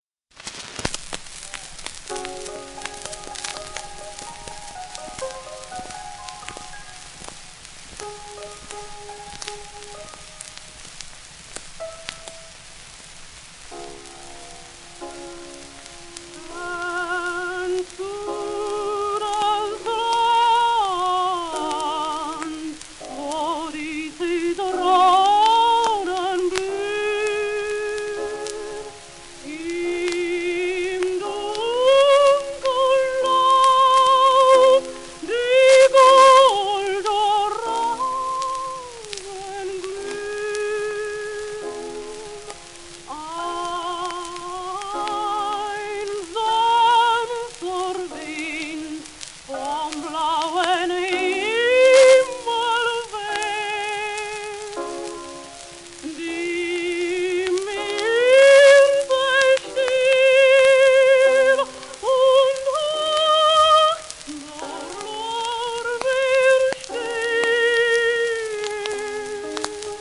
w/ピアノ
盤質A- *キズ,音あり レーベル擦れ
1905年録音
推定回転数84rpm位？